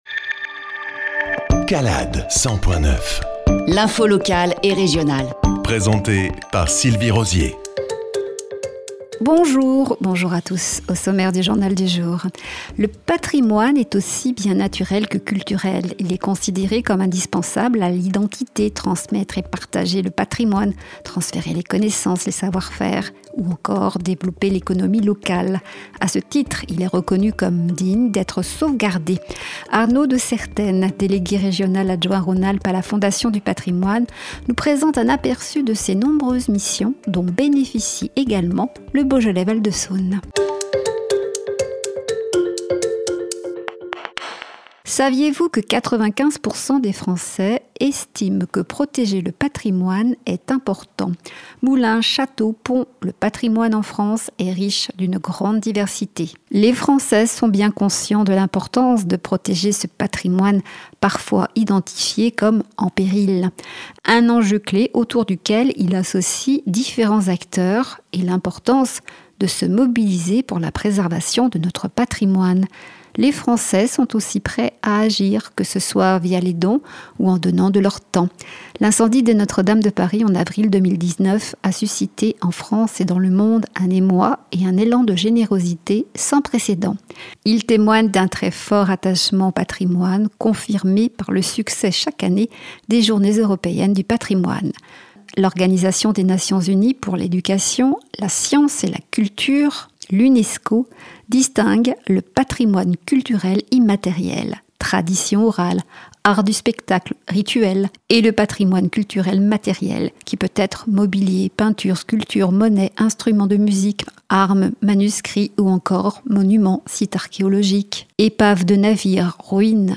JOURNAL – 060525